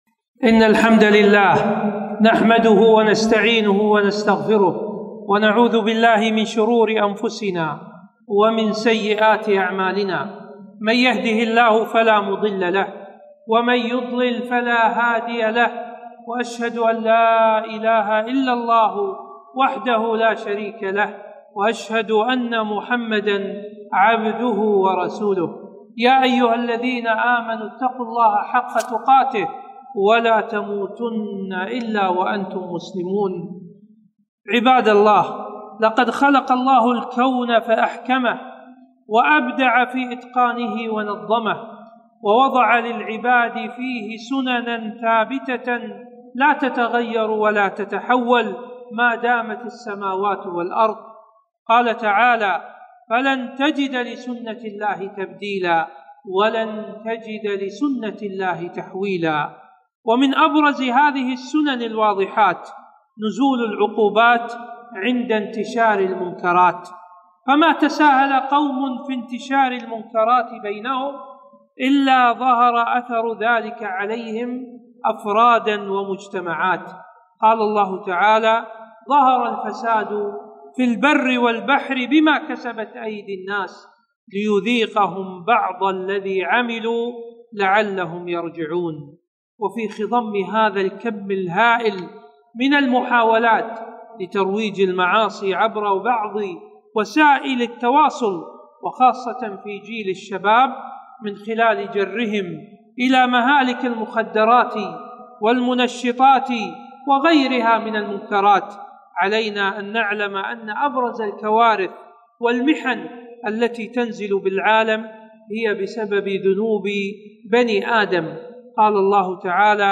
خطبة - آثار الذنوب والمعاصي